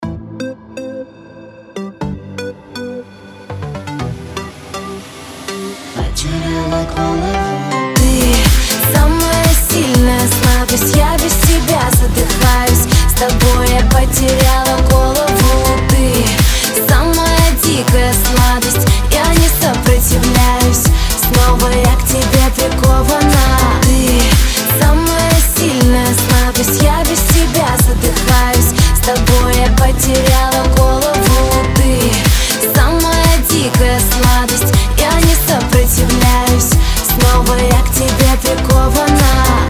• Качество: 320, Stereo
Красивый и романтичный рингтон